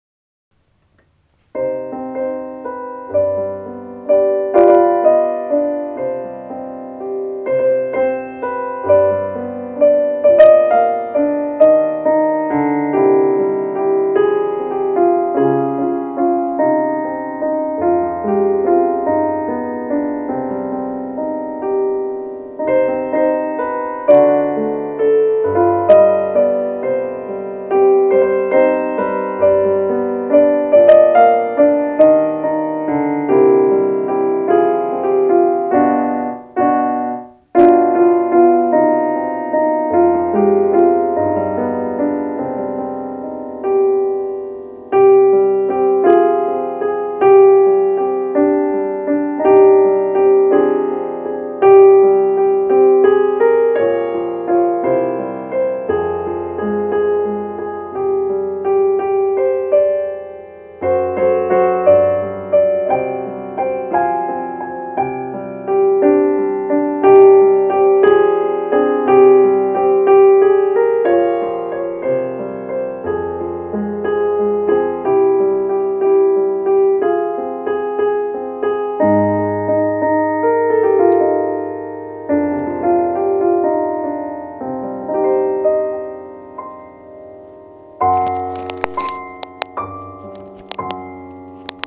מעלה פה ביצוע מהיר ולא מושלם אבל אתם תזהו את הדברים המגניבים כבר(REC067.WAV )